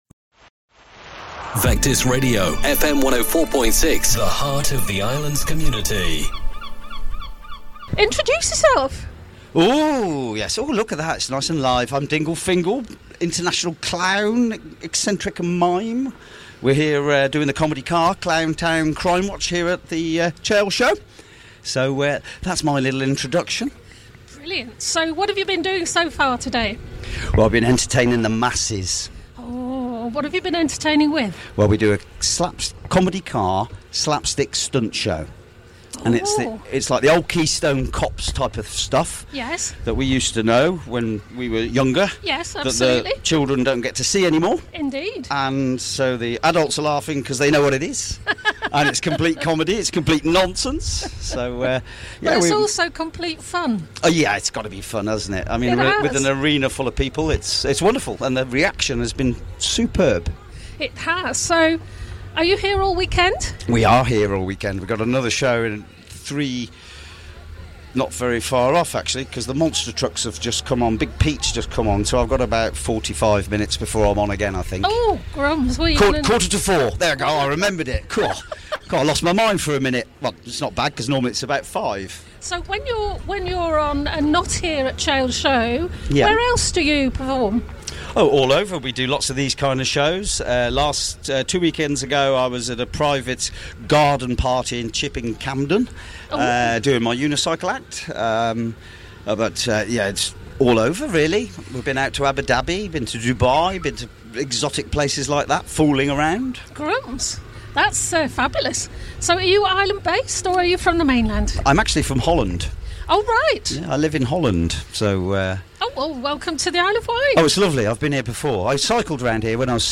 at the Chale Show 2025